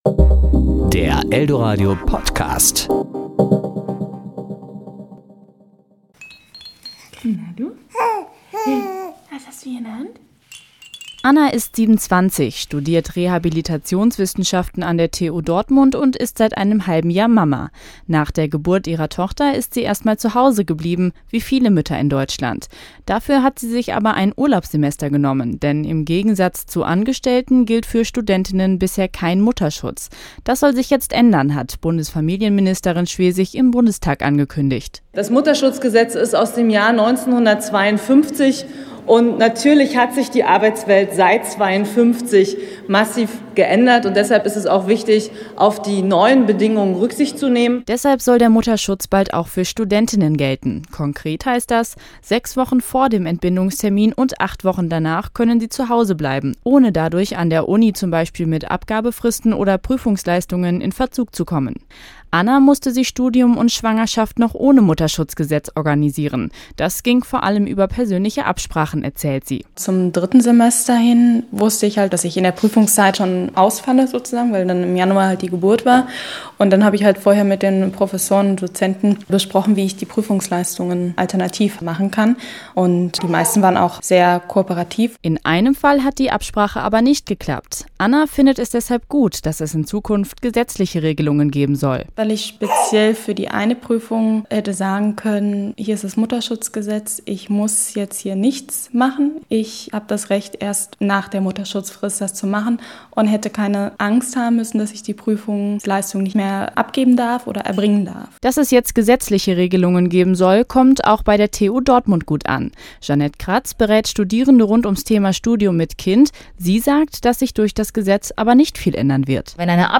Serie: Beiträge  Ressort: Wort  Sendung: Toaster